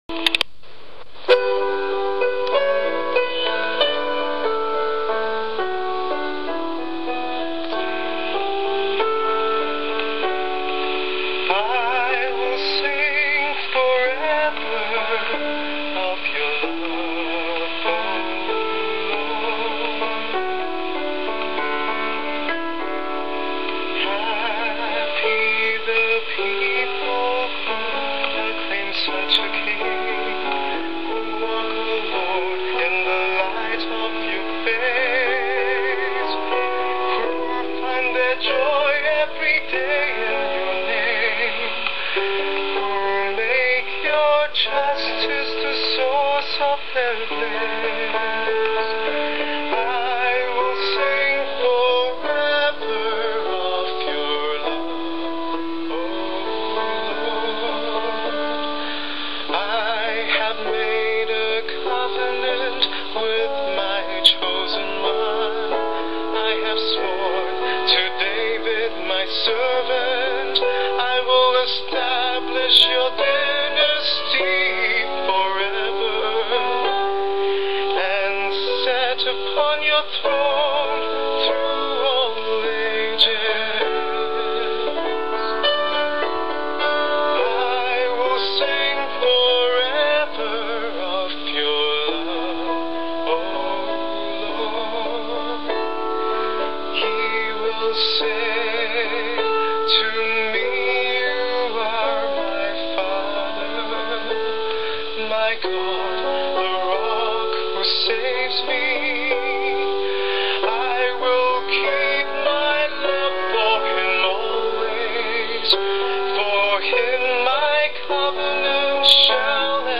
8 PM Christmas Eve Mass, 24 December
Psalm